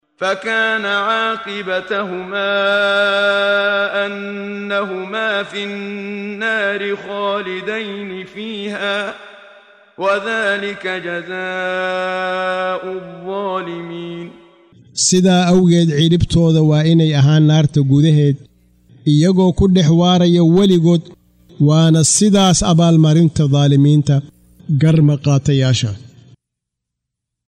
Waa Akhrin Codeed Af Soomaali ah ee Macaanida Suuradda Al-Xashar ( Kulminta ) oo u kala Qaybsan Aayado ahaan ayna la Socoto Akhrinta Qaariga Sheekh Muxammad Siddiiq Al-Manshaawi.